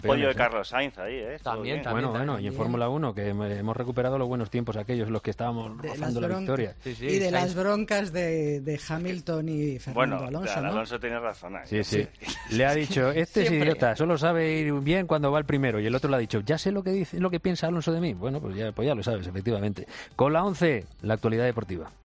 En la tertulia del programa se ha comentado uno de los momentos más polémicos del Gran Premio de Bélgica de Fórmula 1